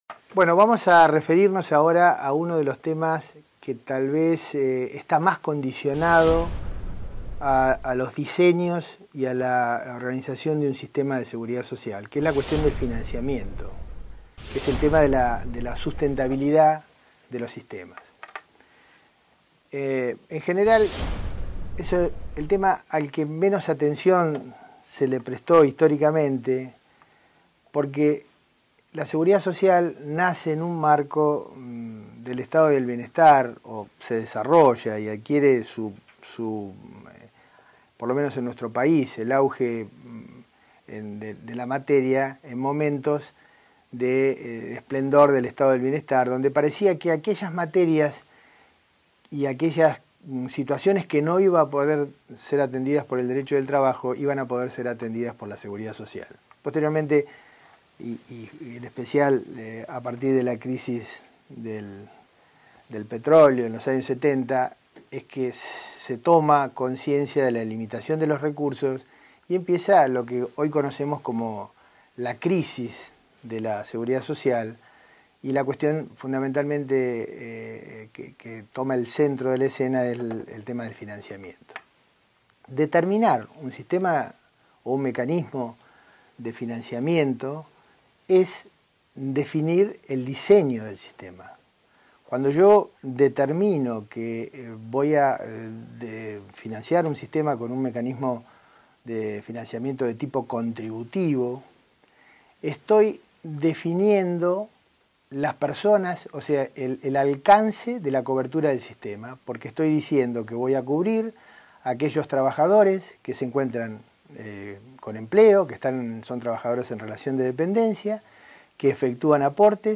Consecuentemente, el problema de su financiamiento es un tema recurrente, razón por la cual expone los diferentes sistemas de financiación existentes, entre los cuales se pueden nombrar: Contributivo, Prestaciones Universales, de Primas Escalonadas y/o de Capitalización Individual, ejemplificando cada uno de ellos. Audio de la clase Descargar clase en formato MP3 MP3 � Anterior - Inicio